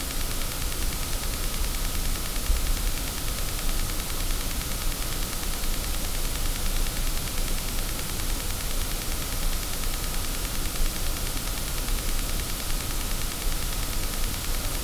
Треск из твиттера монитора Adam S3X-H
Купил мониторы Adam S3X-H из одного идёт тихий треск (прикрепил запись к посту).
Проблема в мониторе, проверял и питание и без источника звука.